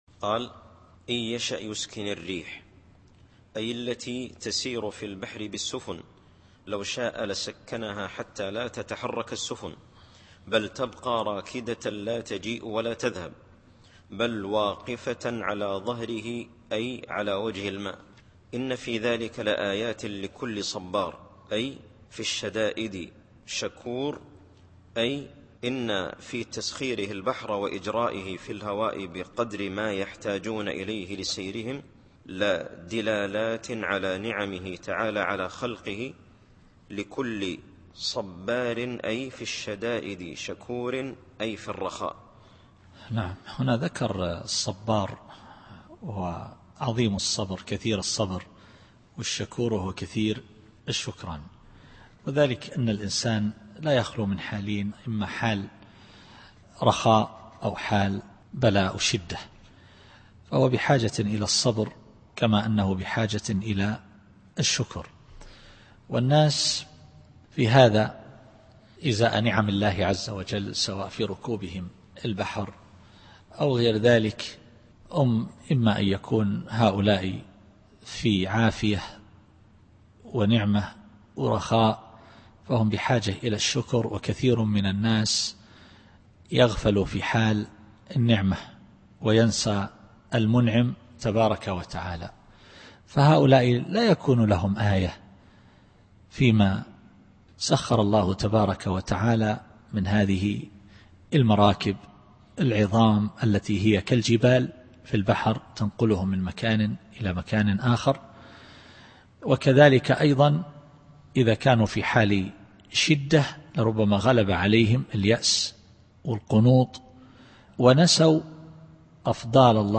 التفسير الصوتي [الشورى / 33]